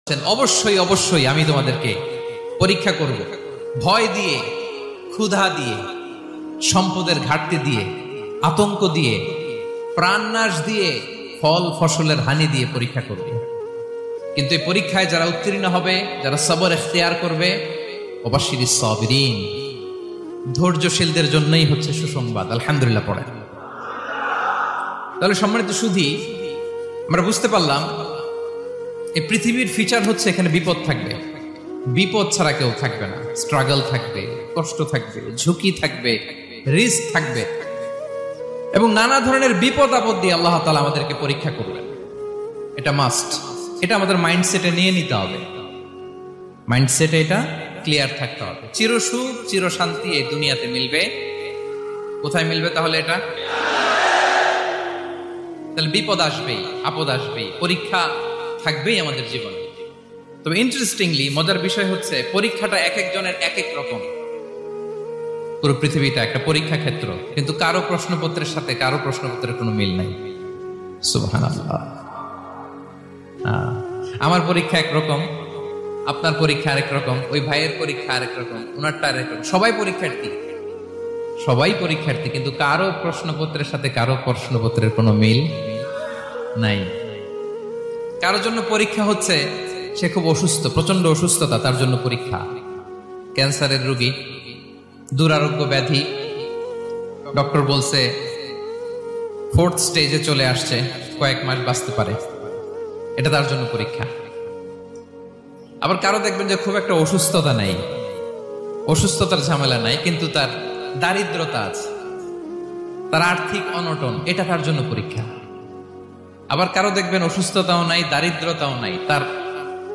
waz.